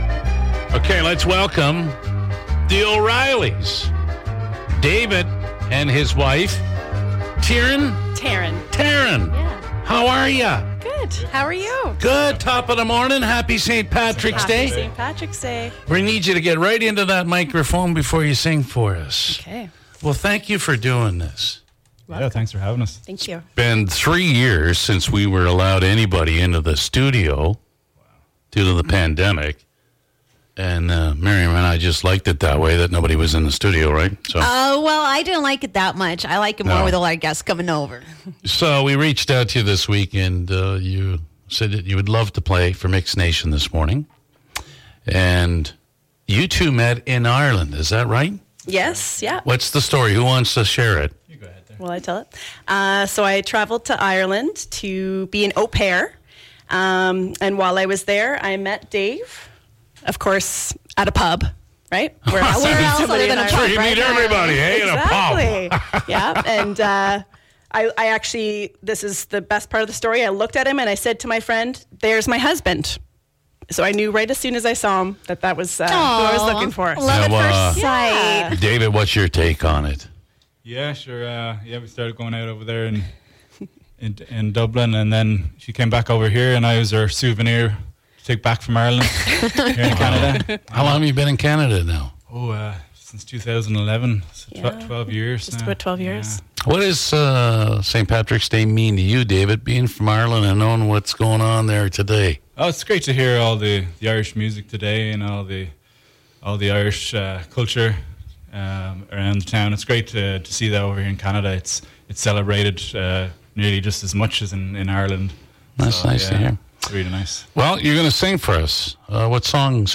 Lots of fun and laughs all around!
You can also watch how it all happened in Studio MIX97 here.